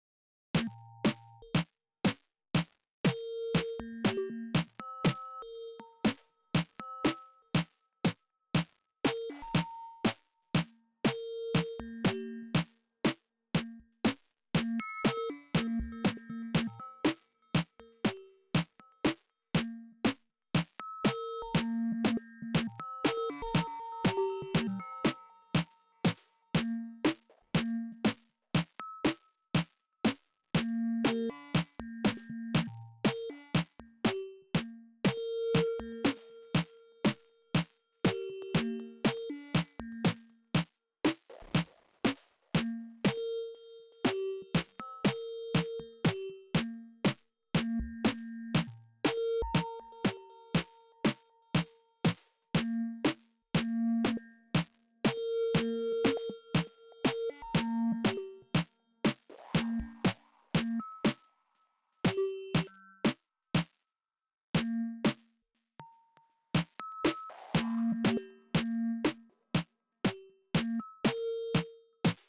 More single track of FM Drum fun: